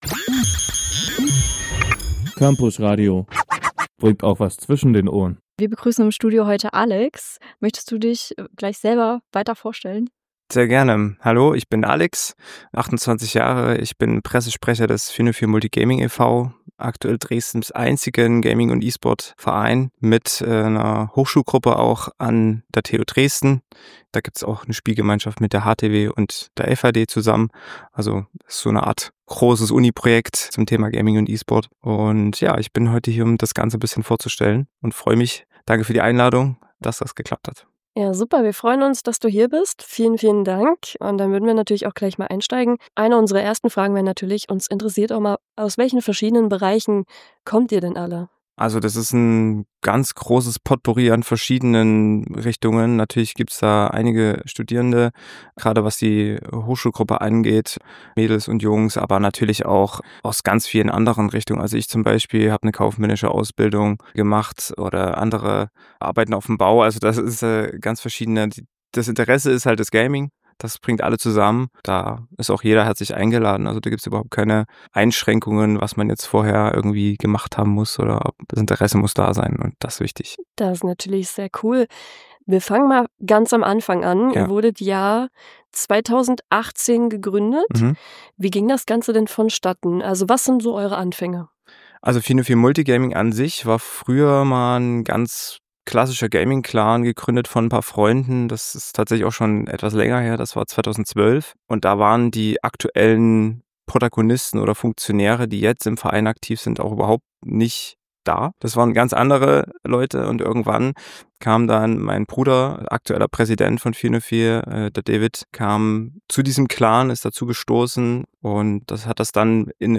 Sachsens größter Gaming & E-Sports Verein, der lange ein „Hidden Gem“ unter den Hochschulgruppen an der TU Dresden, HTW und FAHD war, hat nun im Interview alle Fragen für Neueinsteiger beantwortet und steht offen für neue Mitglieder bereit.
404-gaming-interview_fertig_geschnitten.mp3